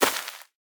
Minecraft Version Minecraft Version latest Latest Release | Latest Snapshot latest / assets / minecraft / sounds / block / rooted_dirt / step2.ogg Compare With Compare With Latest Release | Latest Snapshot
step2.ogg